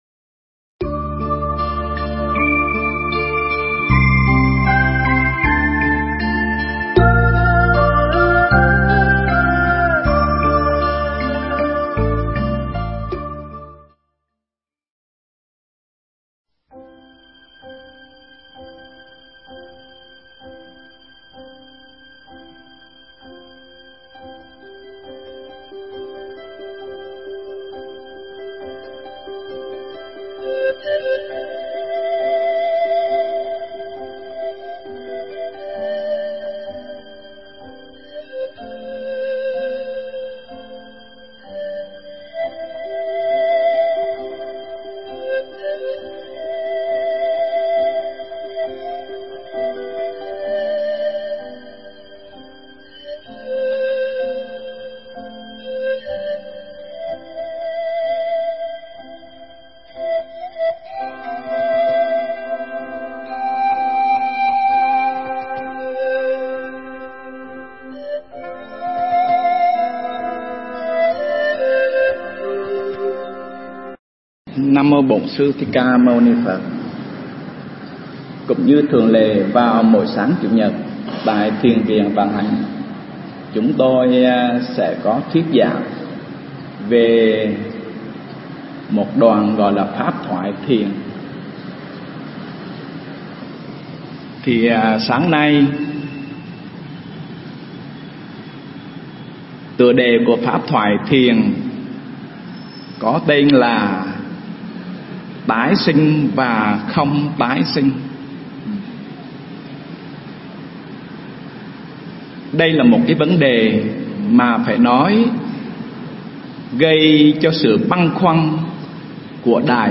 Nghe Mp3 thuyết pháp Tái Sanh Và Không Tái Sanh